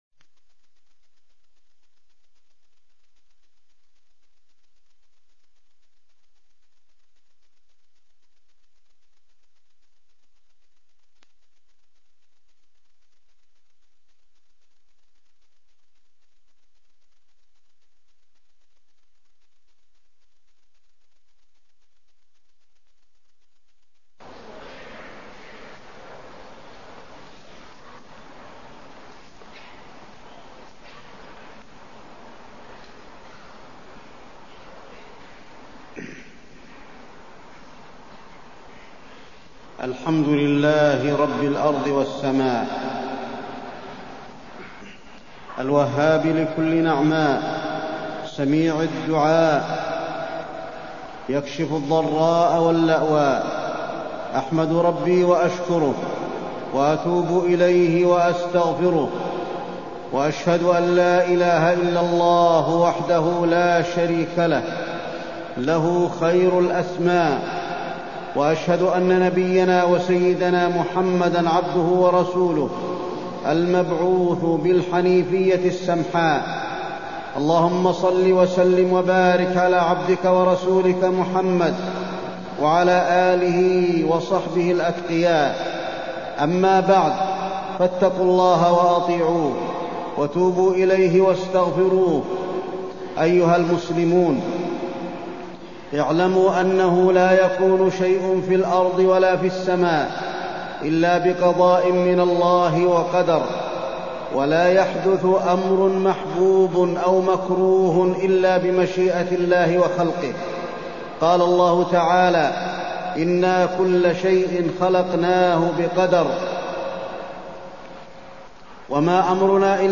تاريخ النشر ٢٠ ربيع الثاني ١٤٢٤ هـ المكان: المسجد النبوي الشيخ: فضيلة الشيخ د. علي بن عبدالرحمن الحذيفي فضيلة الشيخ د. علي بن عبدالرحمن الحذيفي أحداث الرياض